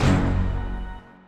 MB Hit (6).wav